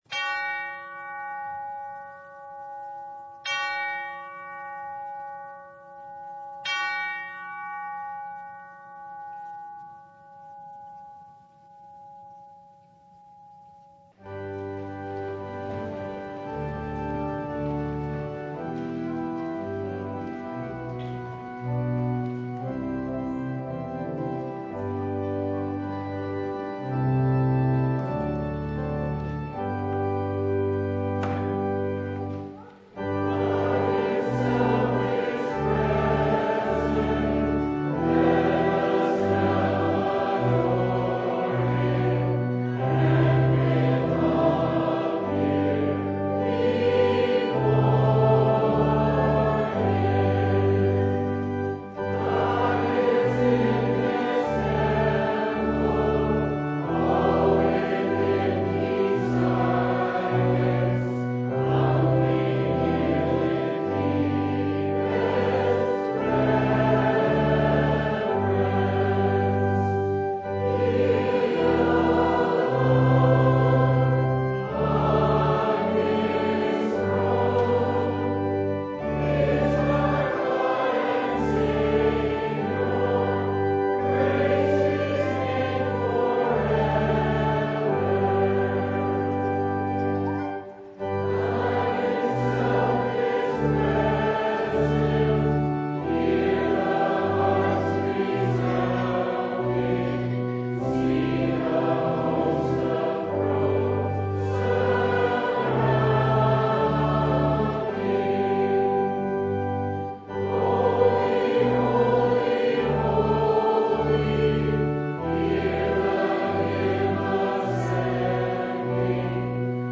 Mark 6:1-13 Service Type: Sunday What do we do with unbelief?